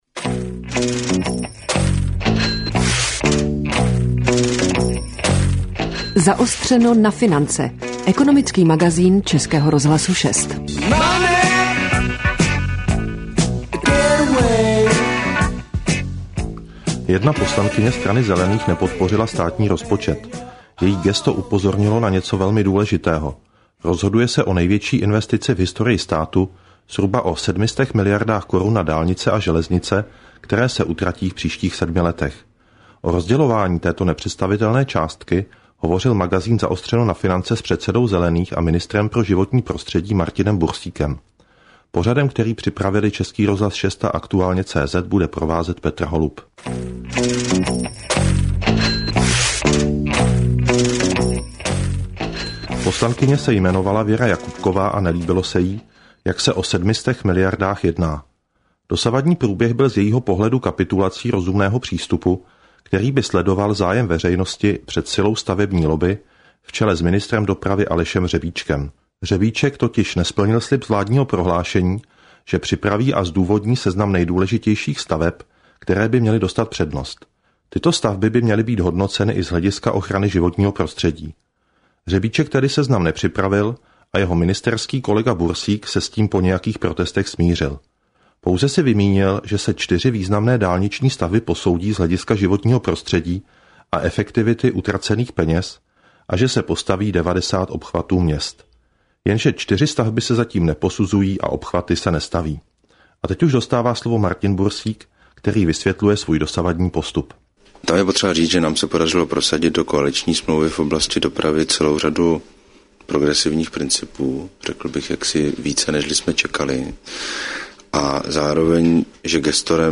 Audio z�znam rozhovoru najdete zde.